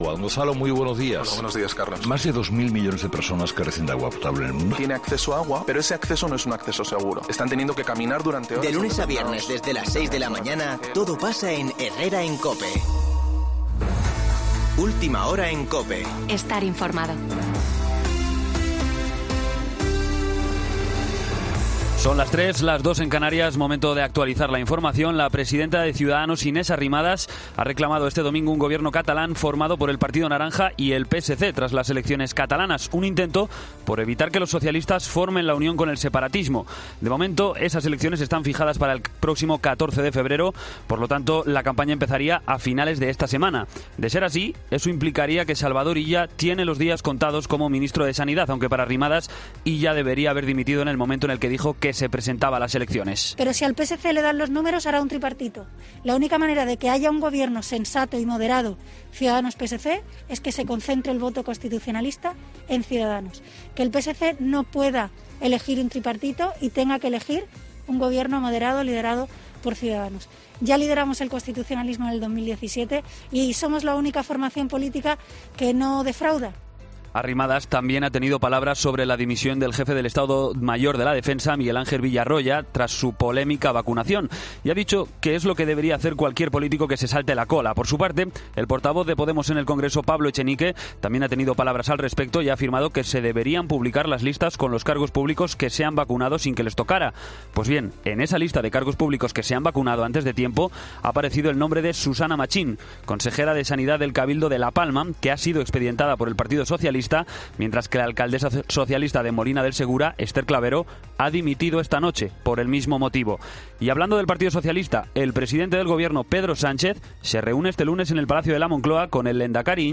Boletín de noticias COPE del 25 de enero de 2021 a las 03.00 horas